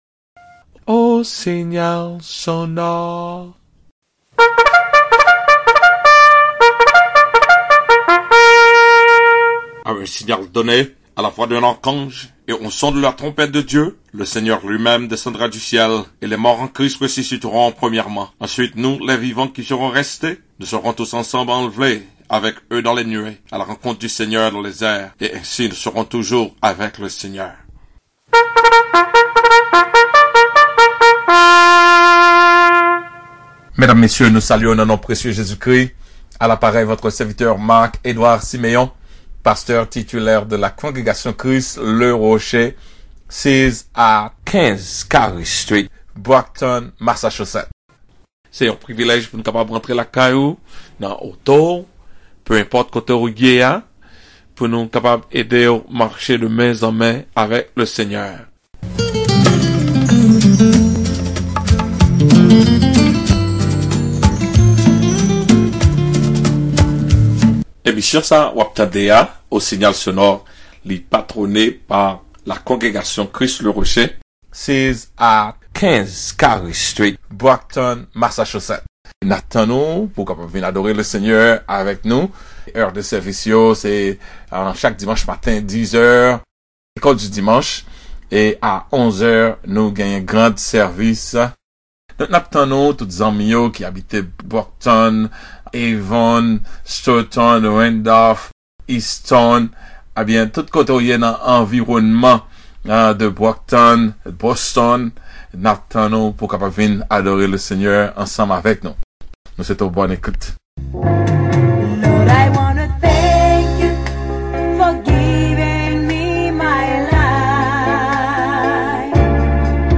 This message is from the radio program Au Signal Sonore #128.